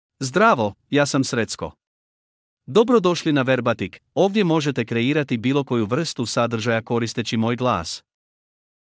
Srecko — Male Croatian (Croatia) AI Voice | TTS, Voice Cloning & Video | Verbatik AI
Srecko is a male AI voice for Croatian (Croatia).
Voice sample
Male